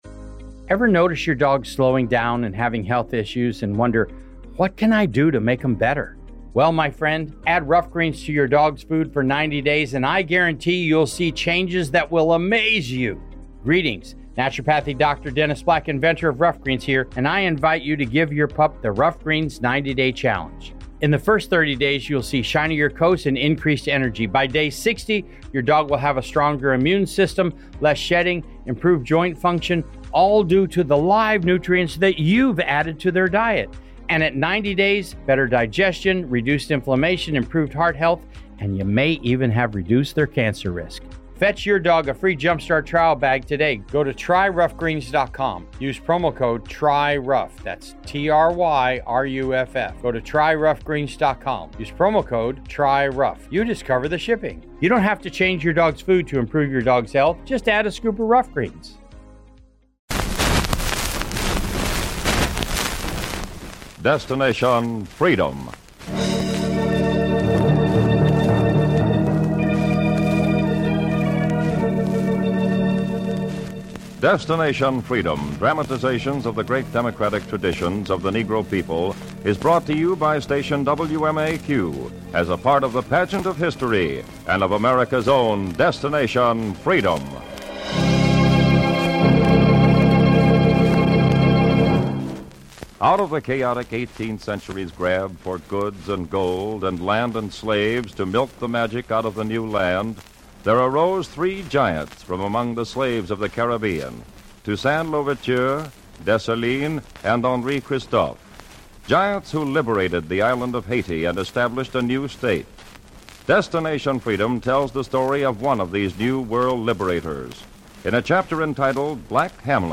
This episode is part of the "Destination Freedom" series, which aired in the late 1940s and early 1950s. The series was known for its dramatizations of the lives of notable African Americans and their contributions to society.